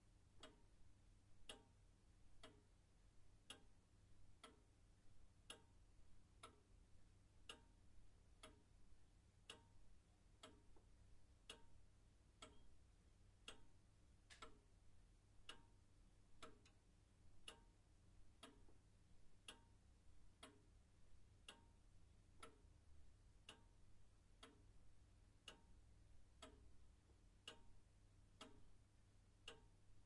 钟声响起
描述：祖父钟的滴答声。
标签： 环境 时钟 爷爷 滴答
声道立体声